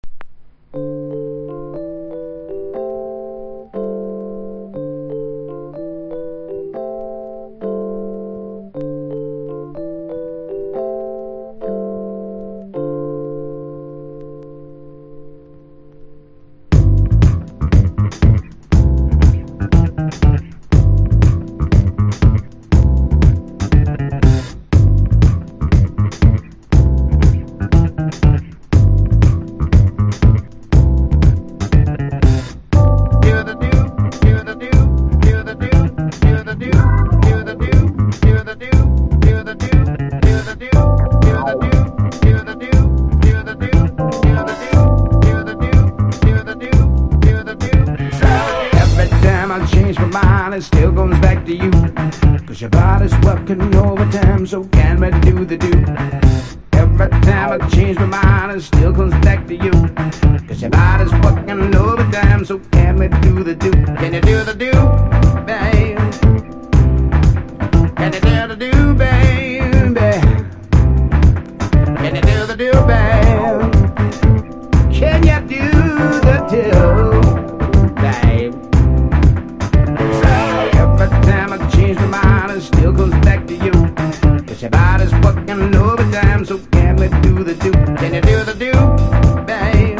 ディープでジャジーなサウンドをベースにファンキーでソウルフルな歌モノに仕上げた傑作。ギターカテッィングが最高です。
(MAIN VOCAL)